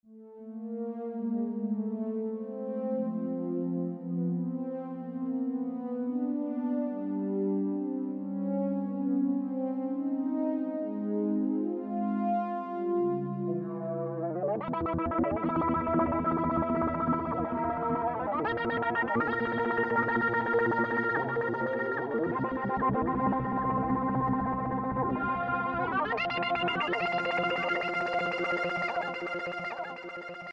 This is something I made long time ago in FL studio.
It was intended as an single player loading screen music but...
it sounds too...computer generated. it just has that vibe not that is all bad. it has a suspense, but falls into this kinda cheesy '50s scifi tone.